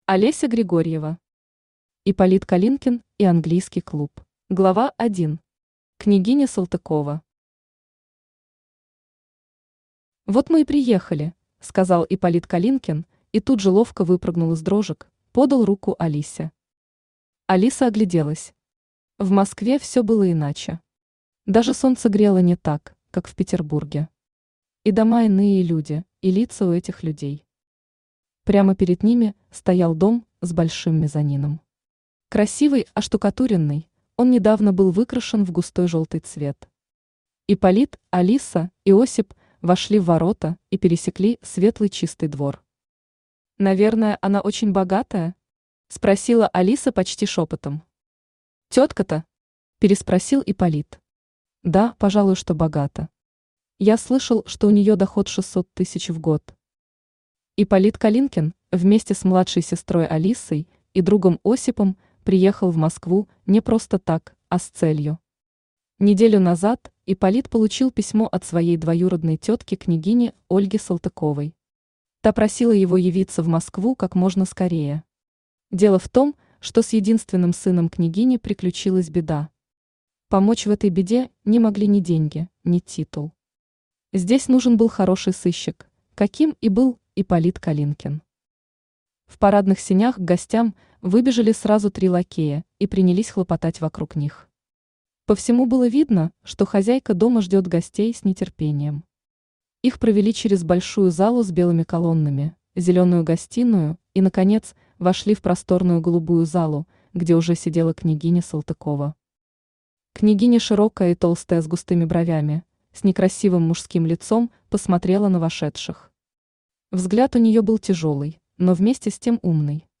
Аудиокнига Ипполит Калинкин и Английский клуб | Библиотека аудиокниг
Aудиокнига Ипполит Калинкин и Английский клуб Автор Олеся Григорьева Читает аудиокнигу Авточтец ЛитРес.